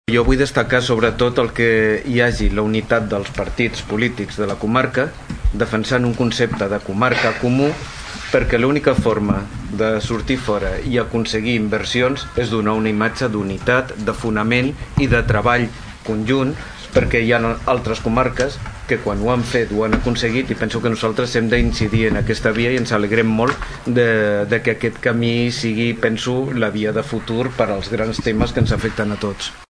Des del PSC, el regidor Rafa Delgado assenyala la importància que els partits polítics de la comarca estiguin units, ja que diu que és la única manera d’aconseguir les coses.